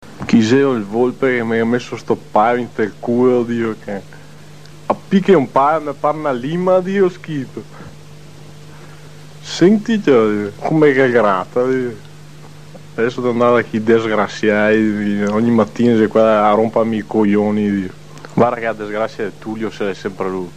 no clue! but sounds a bit like … african!